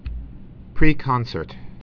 (prēkŏnsûrt, -sərt)